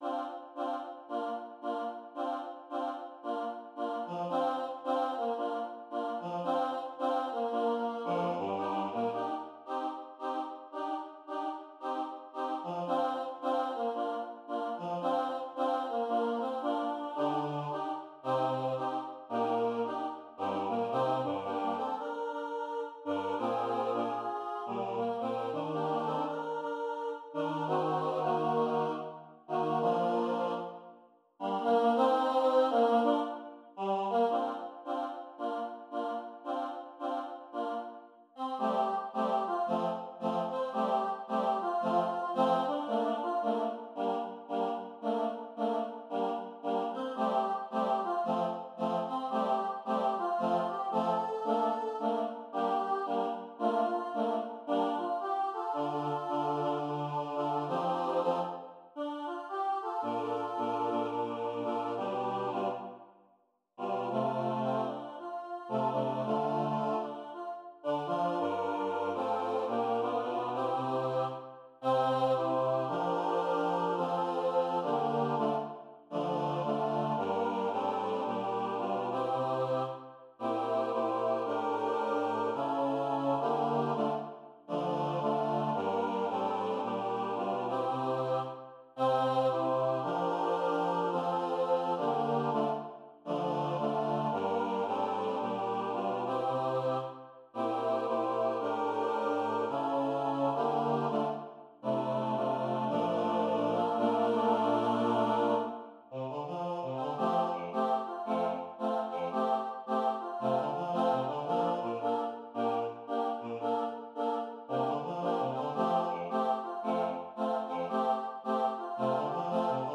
kan synges både a cappella og med akkompagnement